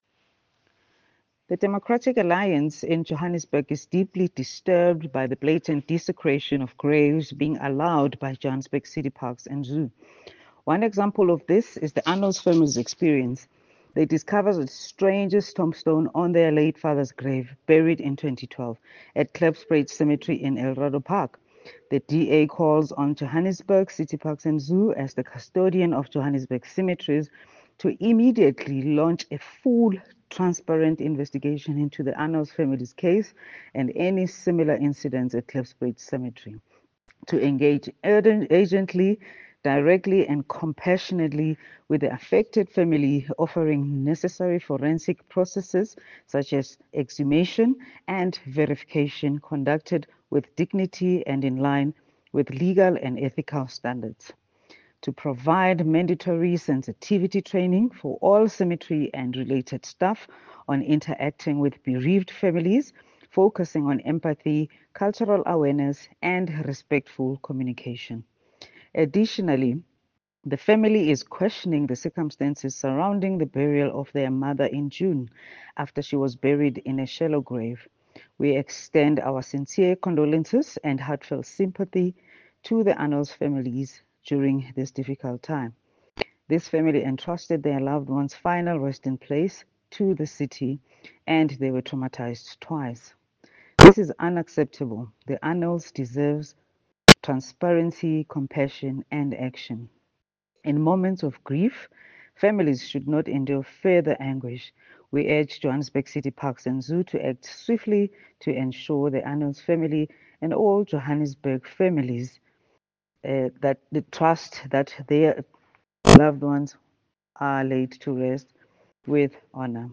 Note to Editors: Please find an English soundbite by Cllr Nonhlanhla Sifumba